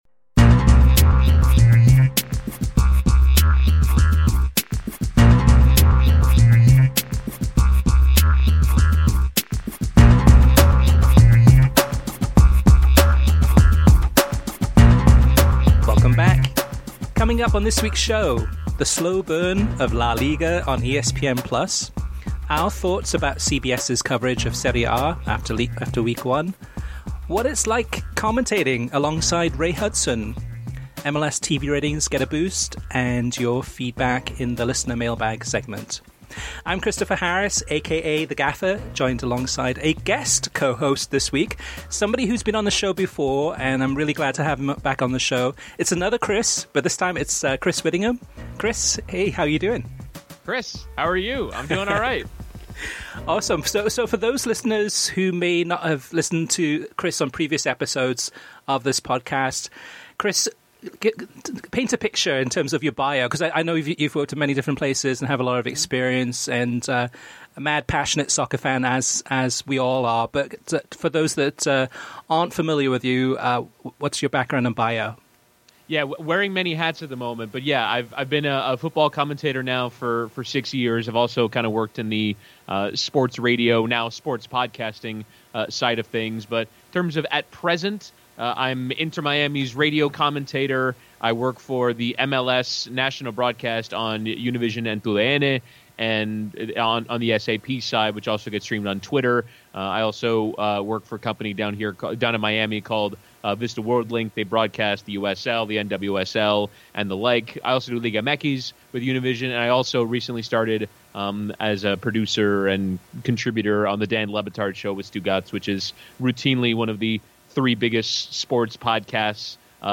guest co-host